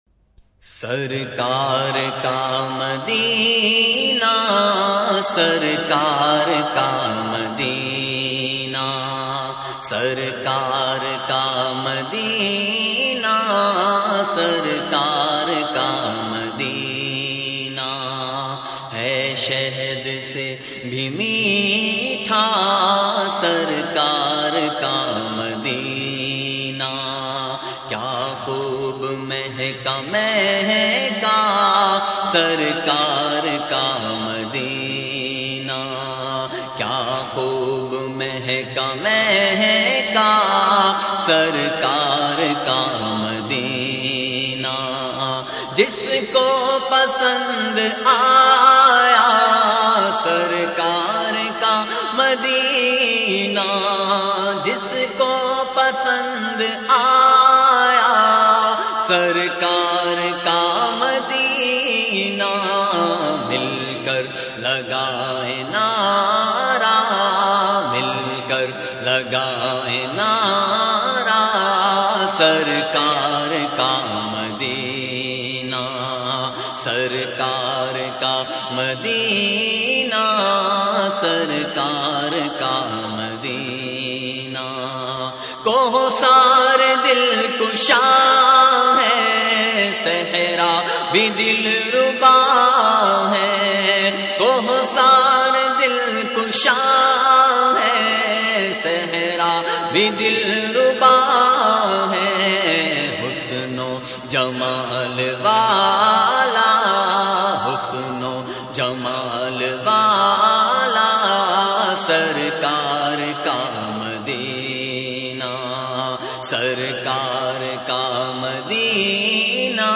Category : Naat | Language : Urdu